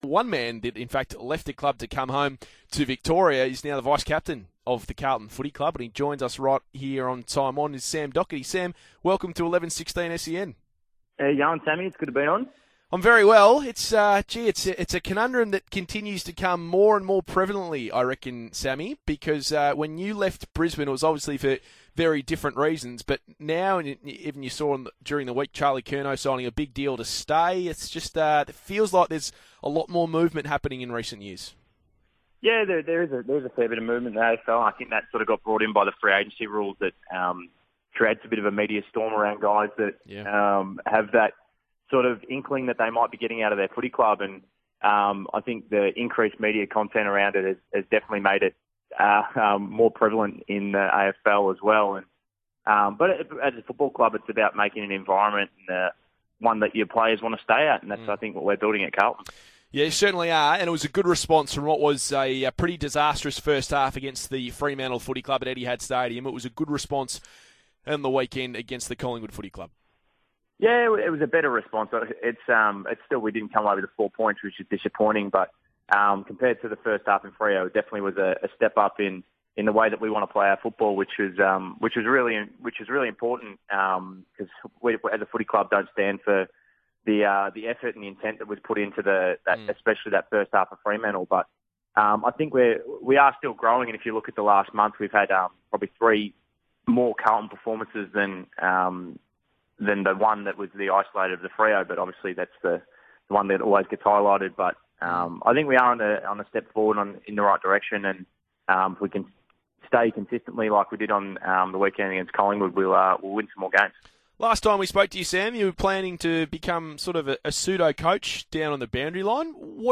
Carlton vice-captain Sam Docherty speaks to SEN 1116 ahead of the Blues' match against Port Adelaide at the MCG on Saturday.